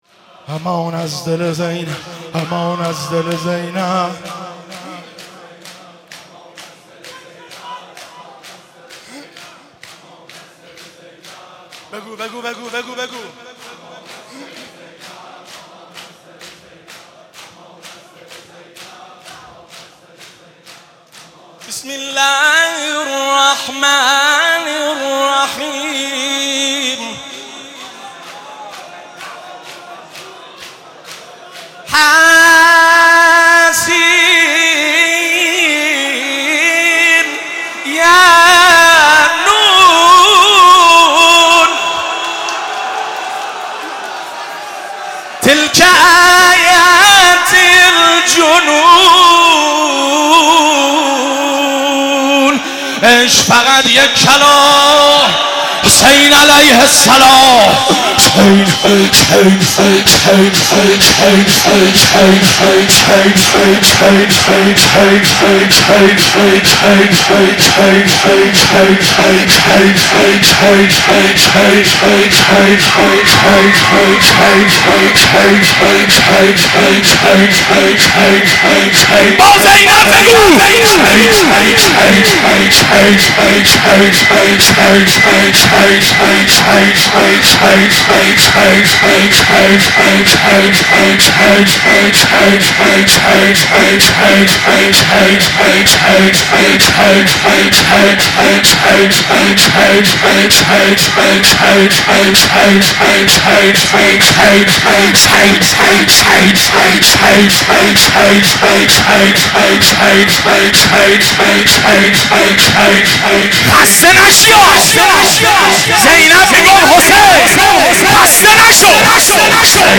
مناسبت : وفات حضرت زینب سلام‌الله‌علیها
قالب : شور